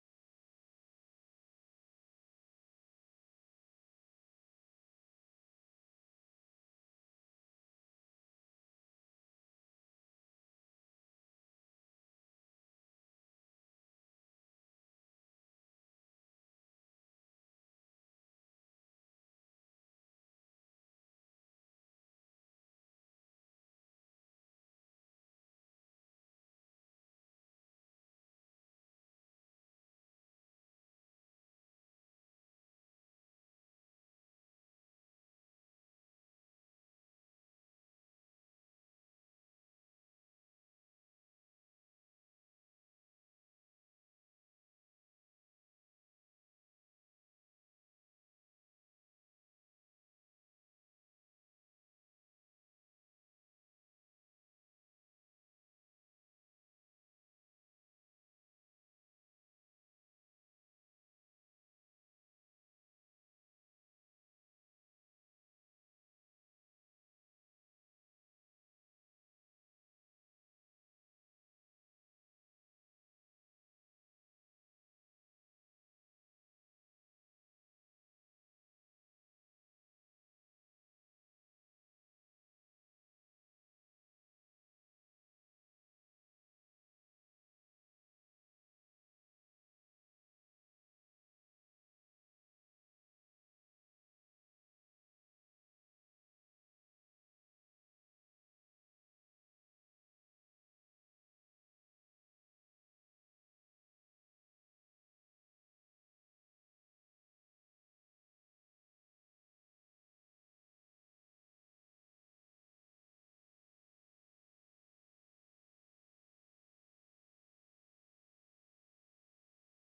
تاريخ النشر ٣٠ ربيع الثاني ١٤٤٠ هـ المكان: المسجد الحرام الشيخ: فضيلة الشيخ د. عبد الكريم بن عبد الله الخضير فضيلة الشيخ د. عبد الكريم بن عبد الله الخضير كتاب الجامع